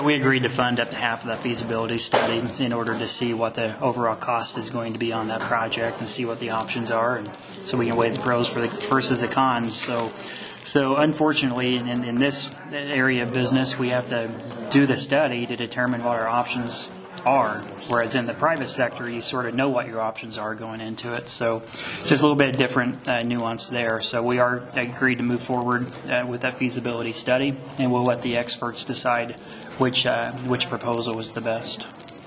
The commissioners today agreed to pay half of that cost…Commissioner President Nathan Gabhart…